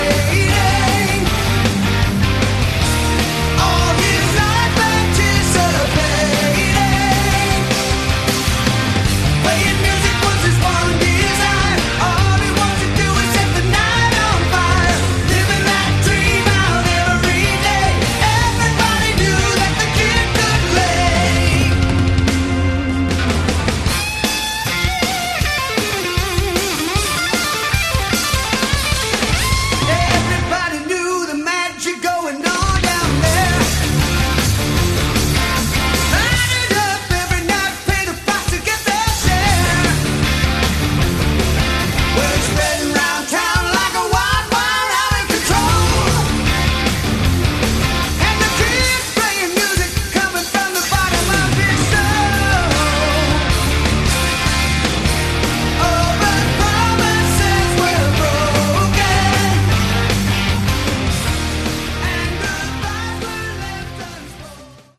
Category: AOR
guitar, bass, keyboards
drums